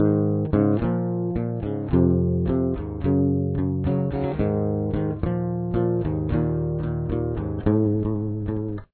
You will need to tune to all flats for this song.
Eb, Ab, Db, Gb, Bb, Eb
Chorus